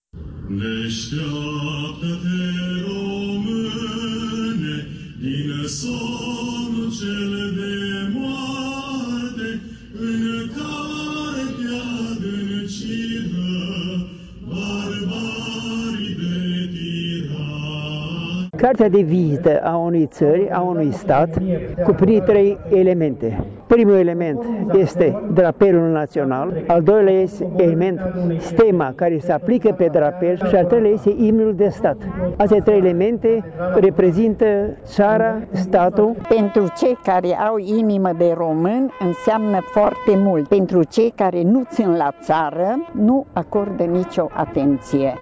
Evenimentele dedicate sărbătoririi imnului național au început de dimineață în Cetatea medievală din Târgu Mureș, unde studenții secției de medicină militară a UMFST au defilat în fața celor prezenți.
Imnul, alături de drapel și stemă, ar trebui să fie respectate de toți românii, spun cei mai în vârstă, care speră ca aceste valori să fie transmise și generațiilor următoare.